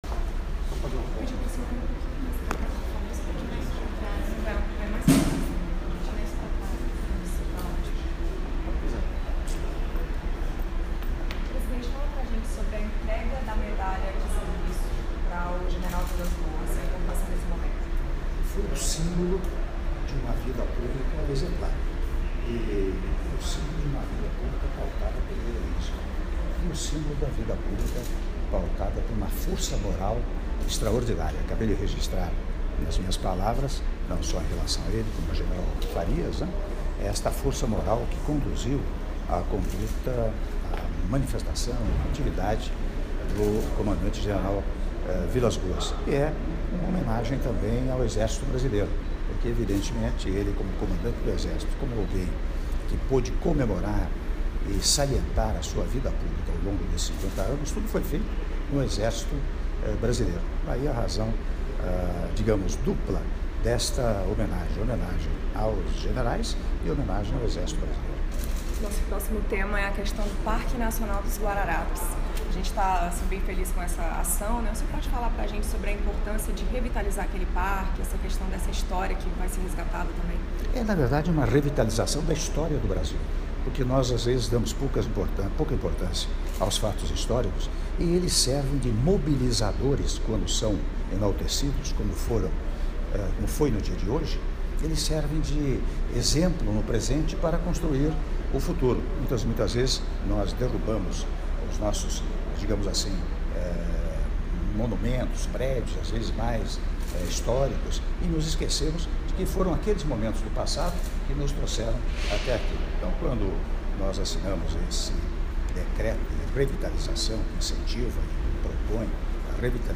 Áudio da entrevista exclusiva concedida pelo Presidente da República, Michel Temer, à Rádio Verde Oliva - ( 03min0s) - Brasília/DF